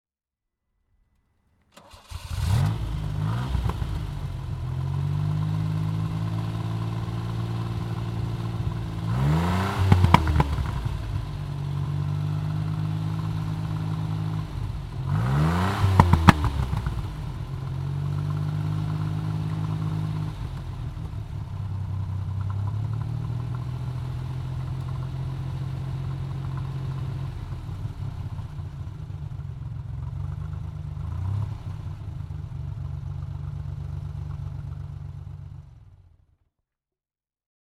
Maserati Merak SS (1977) - Starten und Leerlauf
Maserati_Merak_SS_1977.mp3